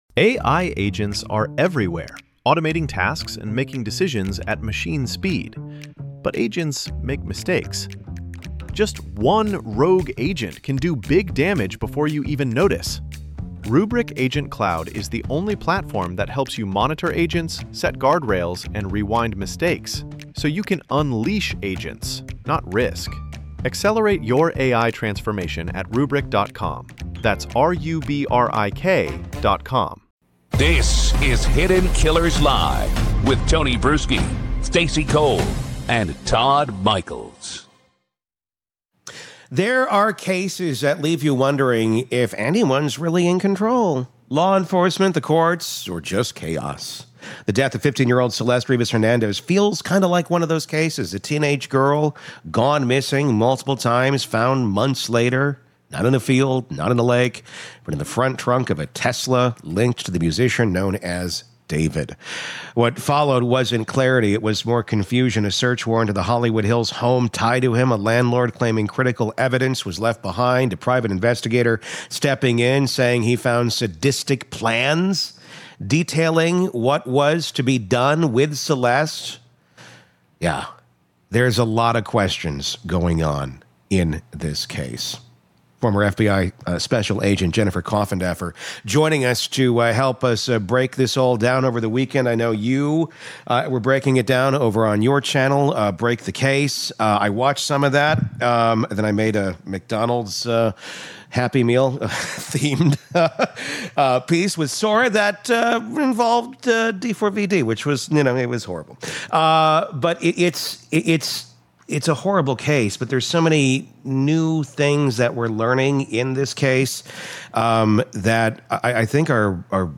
retired FBI Special Agent